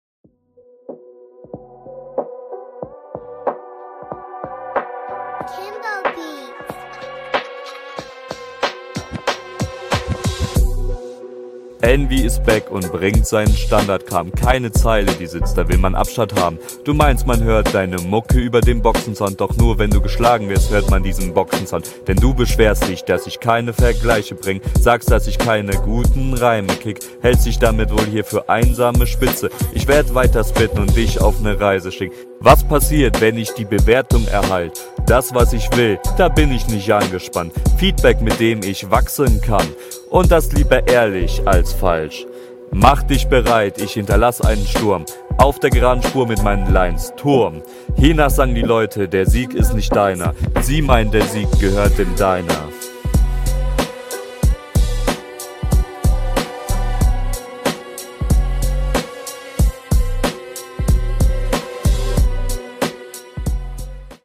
Versuch das weniger wie ein Vortrag zu rappen und ein kleinen wenig mehr auf reime …
Bei dir hört es sich an als würdest du den Text einfach nur Vorlesen meiner …